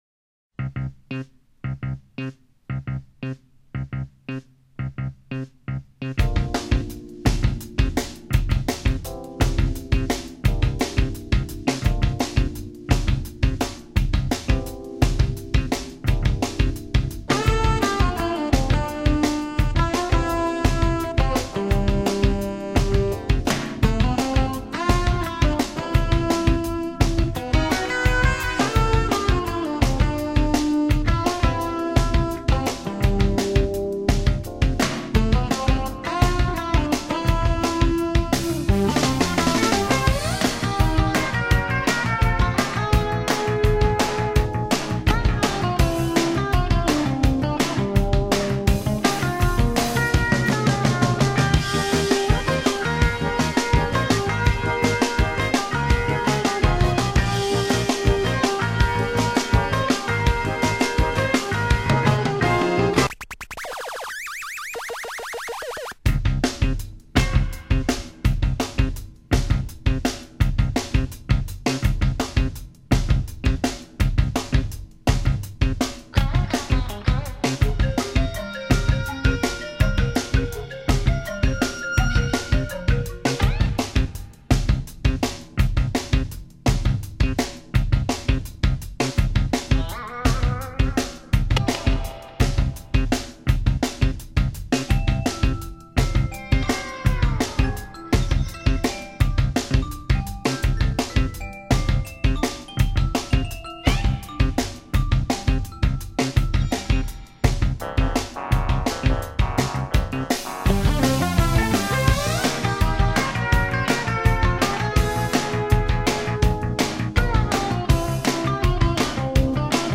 萨克斯管
吉他
键盘
bass
鼓